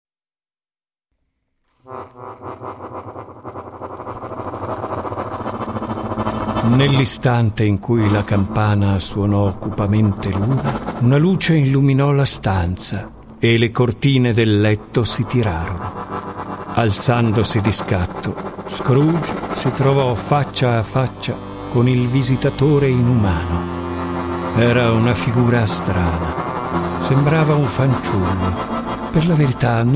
guitar
accordion